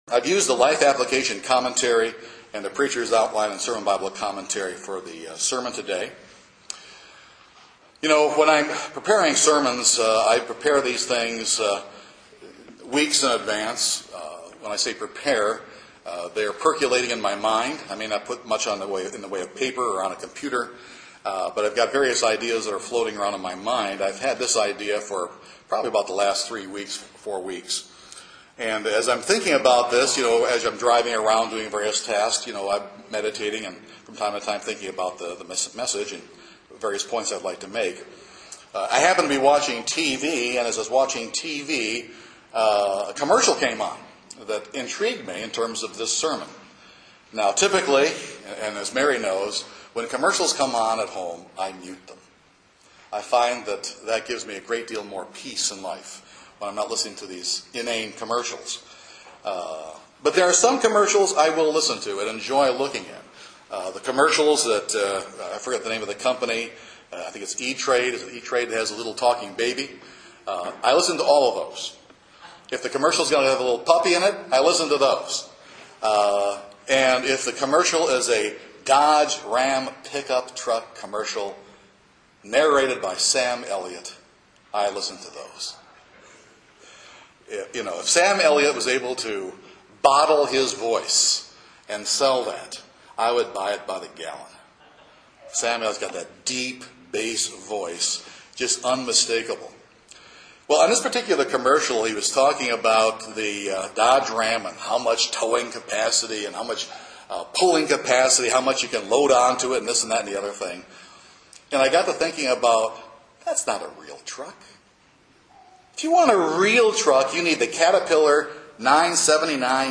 This sermon demonstrates that in order to properly utilize the great power of God’s Spirit we must understand key principles.